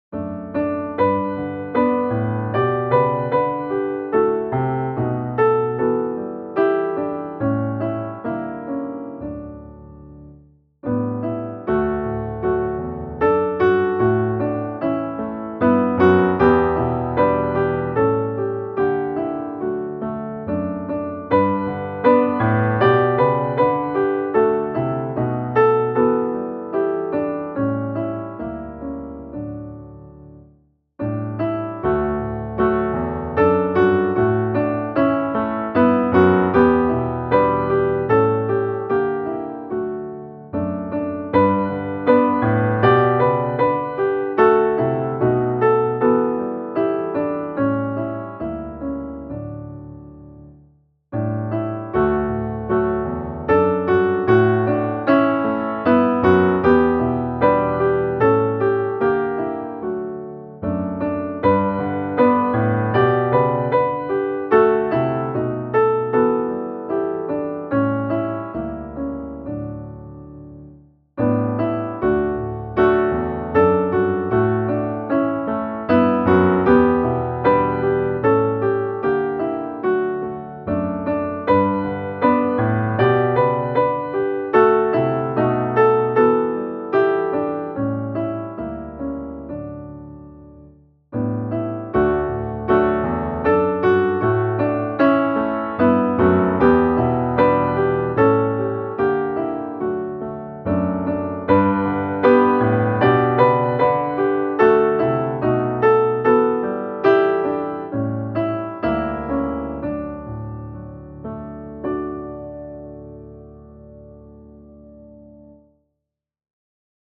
Tryggare kan ingen vara - musikbakgrund
Musikbakgrund Psalm